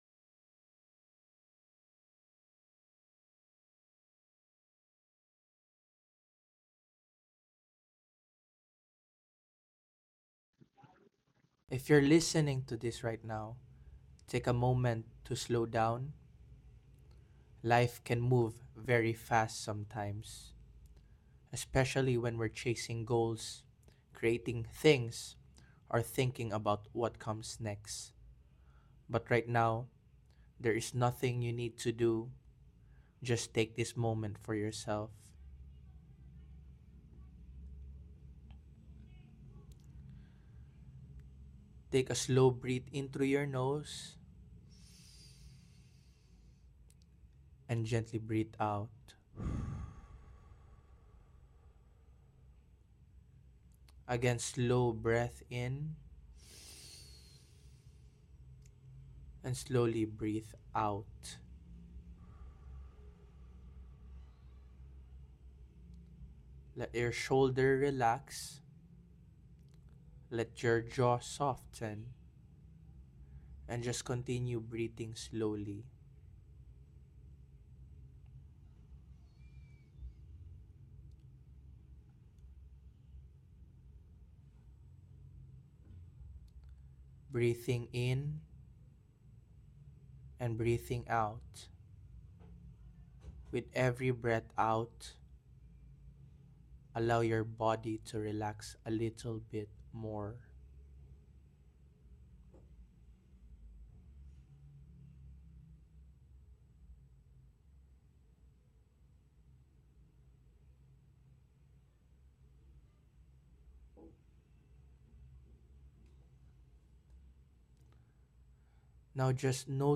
A short guided meditation to slow down, breathe, and reset your mind.